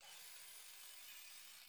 SD_SFX_GrapplingHook_Pull_Start.wav